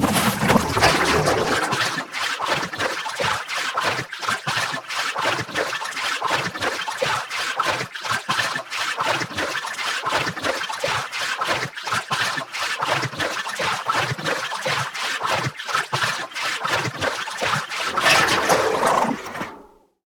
bottle.ogg